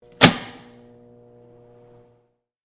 Single Breaker Throw With Hum
Single Breaker Throw with Hum.mp3